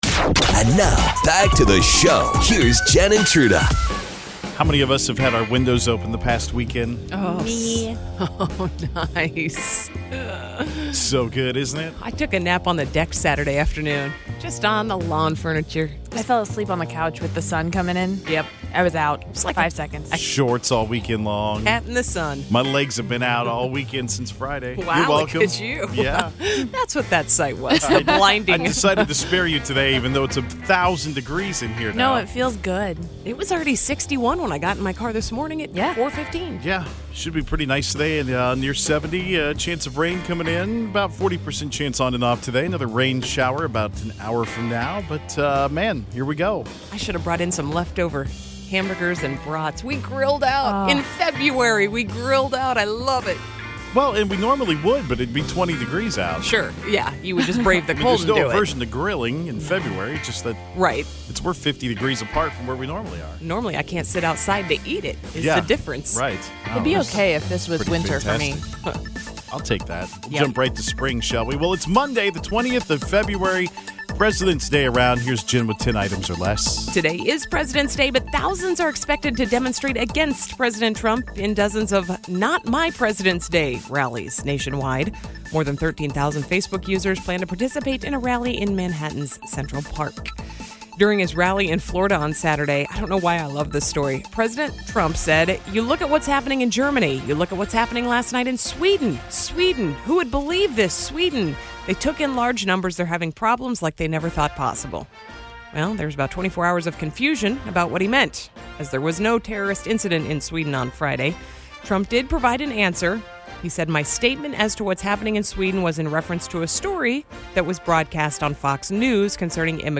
After Entertainment News we took calls from people who have done their own stitches, pulled their own teeth, or basically performed surgery on themselves.